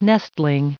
added pronounciation and merriam webster audio
1709_nestling.ogg